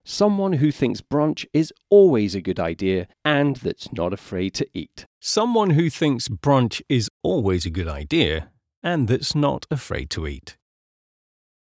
The easiest way to show you this in practice is to share the first ever audition that I submitted on an online casting site (2016) compared to a version of it that I recorded for this blog using my current set-up (2022).
You can hear how the dynamic microphone sounds “tinny” or hollow.
There is also some obvious mouth noise which isn’t great.
That is a good thing as it was recorded in an open room with no sound treatment.